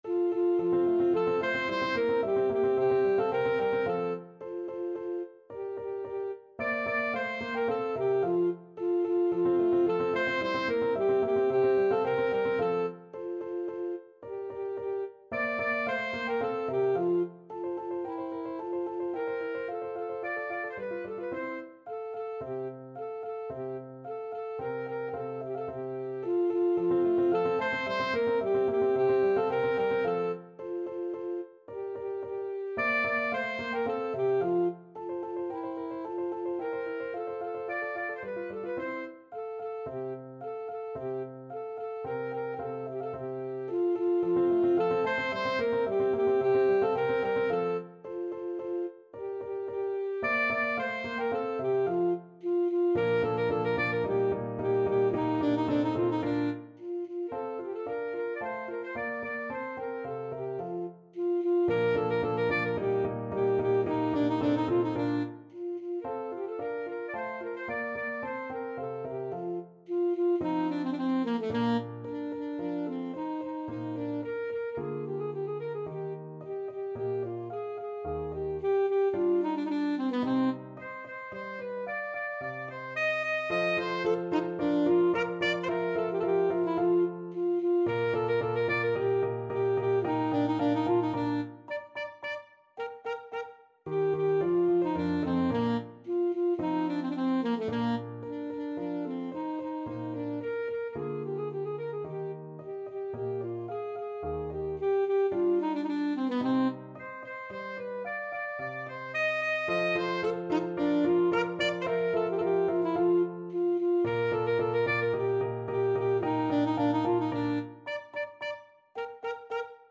Alto Saxophone version
Alto Saxophone
Vivace assai =110 (View more music marked Vivace)
2/4 (View more 2/4 Music)
Classical (View more Classical Saxophone Music)